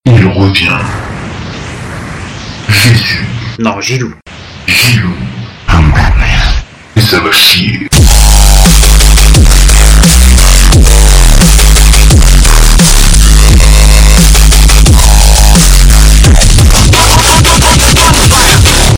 PLAY Jingle 0001
jingle_2.mp3